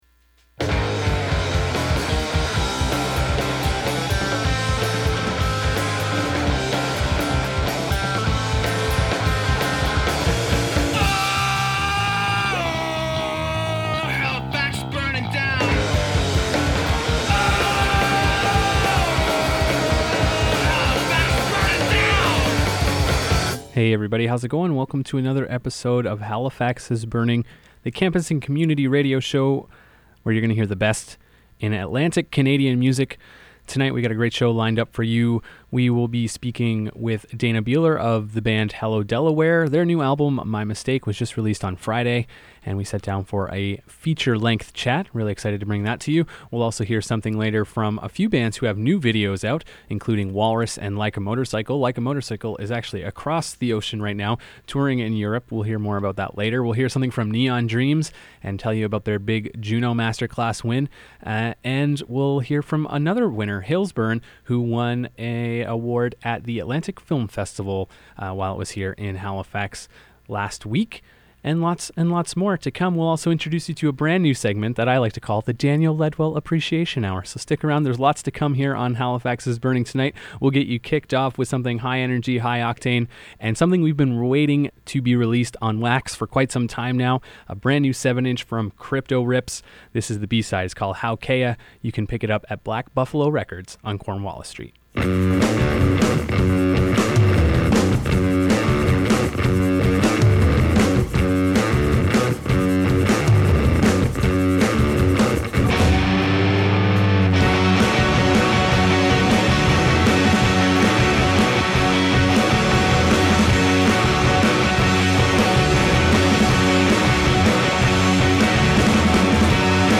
Features an interview with Hellow Delaware + the best independent East Coast music